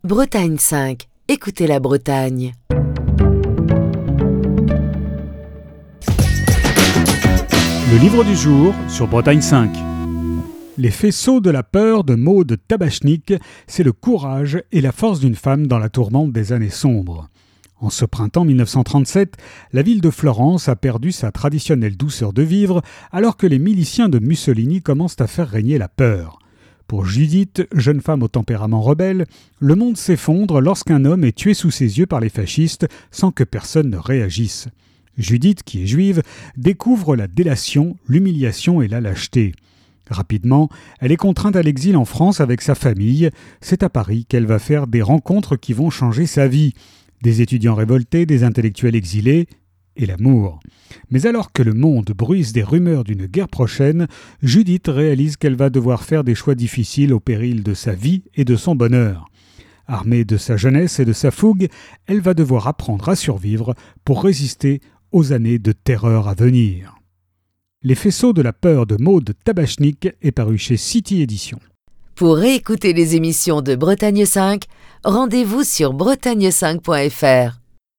Chronique du 11 mars 2022.